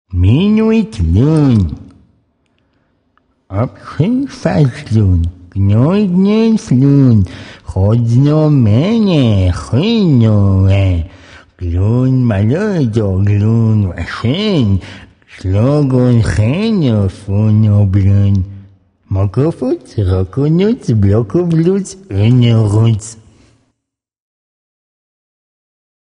Mocko fuds Rocko nuds Blocko vluds Öno ruds [Rezitation anhören]